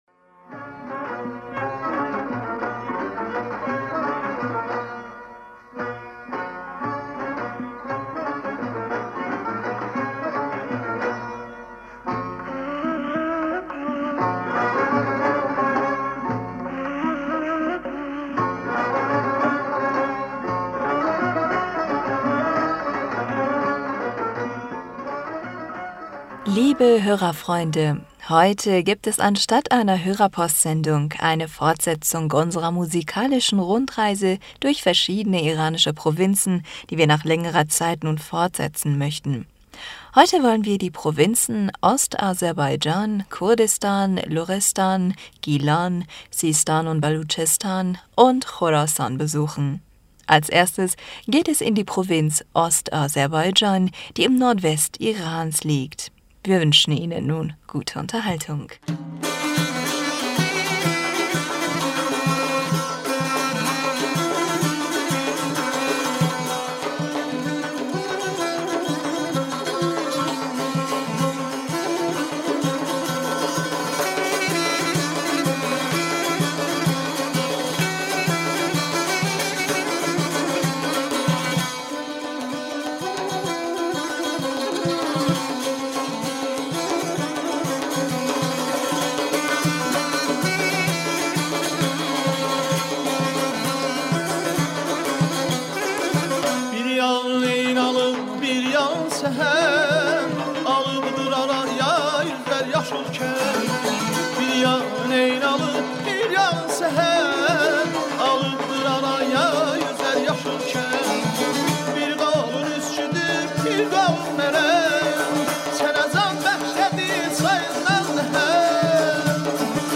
Liebe Hörerfreunde heute gibt es anstatt einer Hörerpostsendung eine Fortsetzung unserer musikalischen Rundreise durch verschiedene iranische Provinzen, die wir nach längerer Zeit nun fortführen möchten.